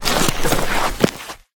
Ledge Climbing Sounds Redone
ledge_grabbing / Vaulting / Light